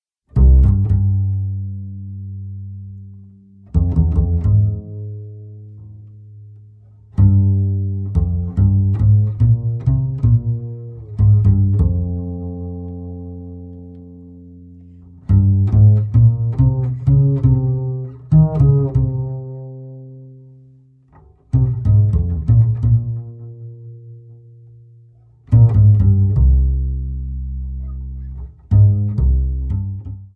2015年8月25、26日　東京・亀吉音楽堂で録音
おなじみのスタンダードナンバーを力強く丁寧 にメロディーを紡いでいく。